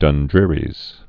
(dŭn-drîrēz)